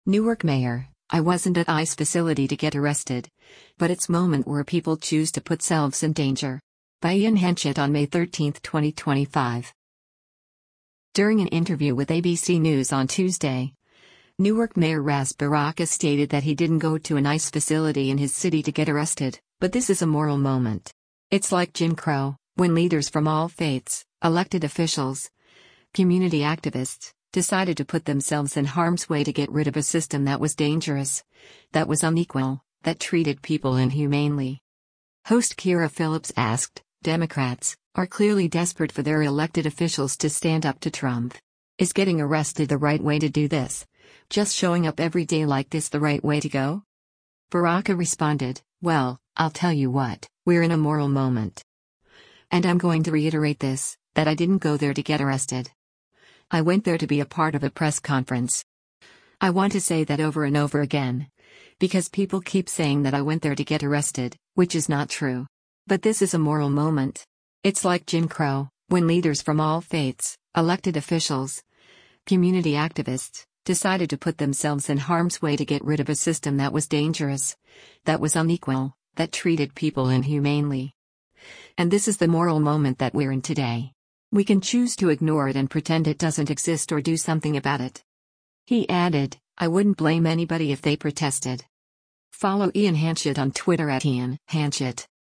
During an interview with ABC News on Tuesday, Newark Mayor Ras Baraka stated that he didn’t go to an ICE facility in his city to get arrested, “But this is a moral moment. It’s like Jim Crow, when leaders from all faiths, elected officials, community activists, decided to put themselves in harm’s way to get rid of a system that was dangerous, that was unequal, that treated people inhumane[ly].”